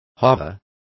Also find out how alberga is pronounced correctly.